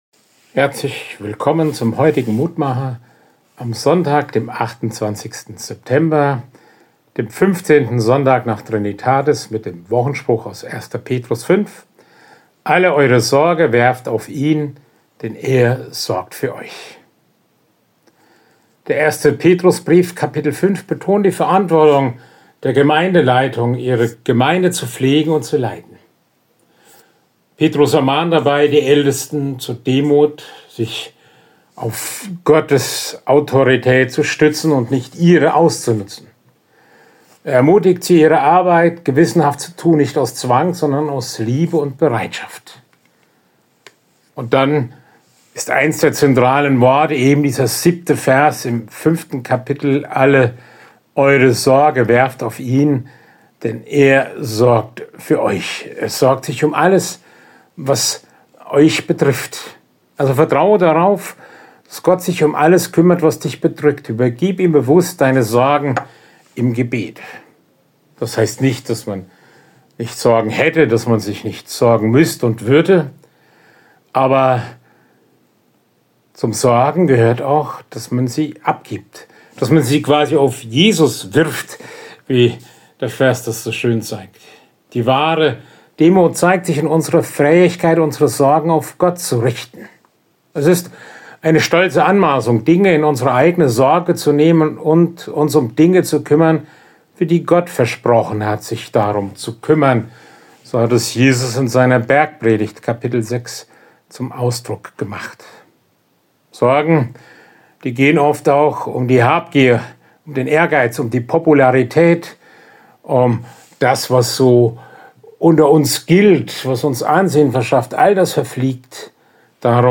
Mutmacher - Kleine Andacht zum Tag